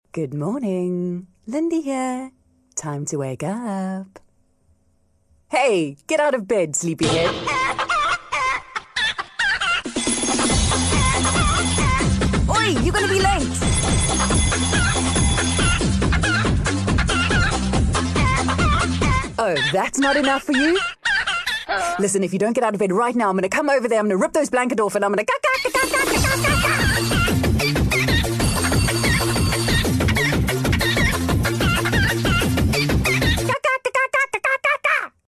Smile Breakfast made their very own alarms for you to download and use on your phone.